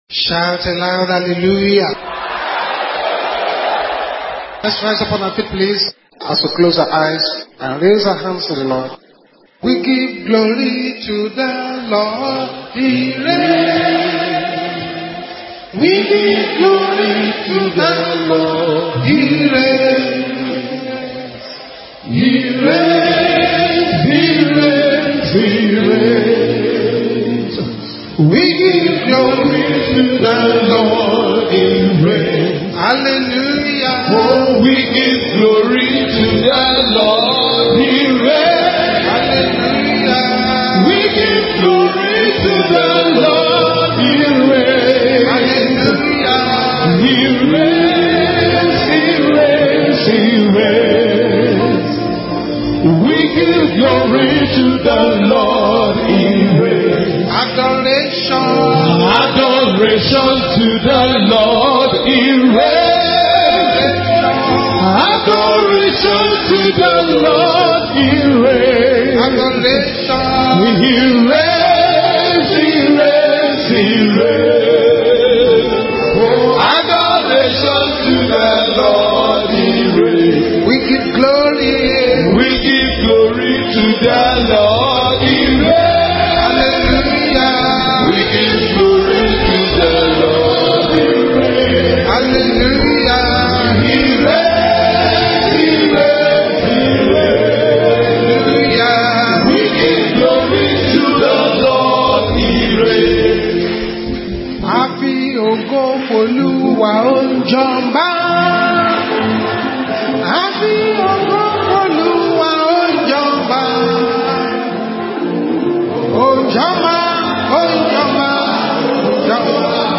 Sermons – Page 2 – MFM Essen,Germany